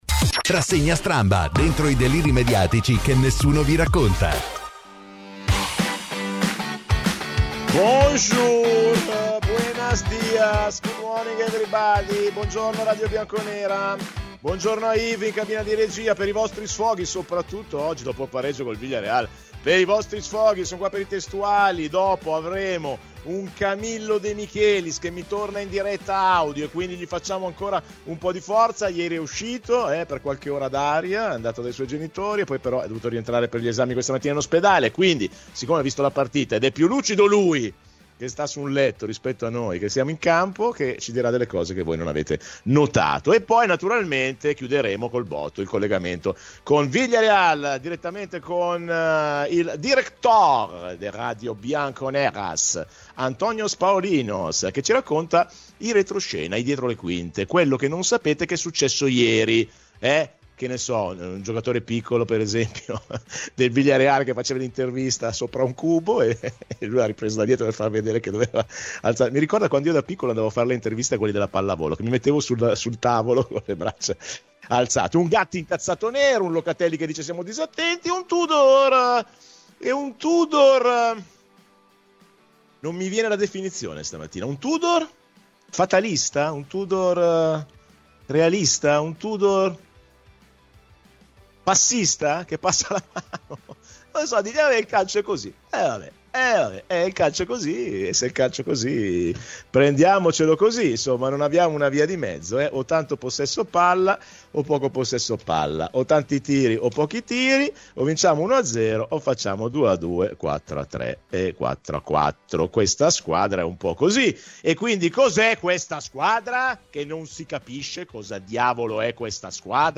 Ospite della Rassegna Stramba su Radio Bianconera